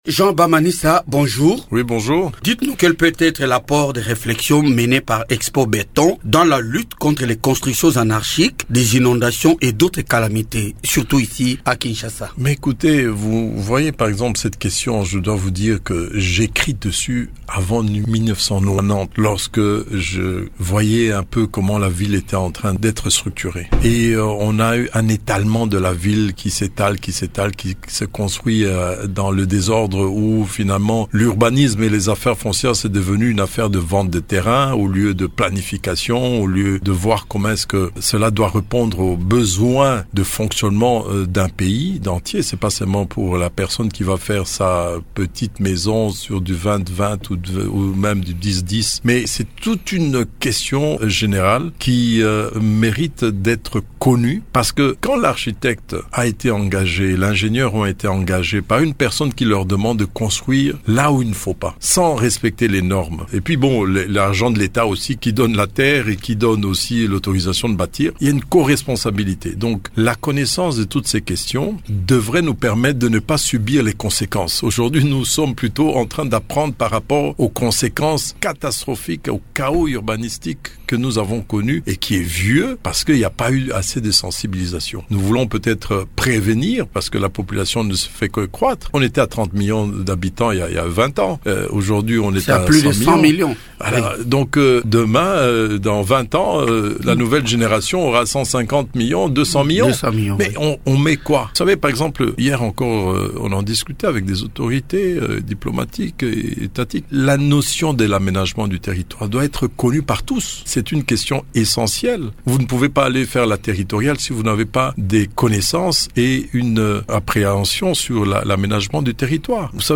Dans une interview accordée Invité à Radio Okapi, il a insisté sur la nécessité de respecter les normes dans la construction des infrastructures.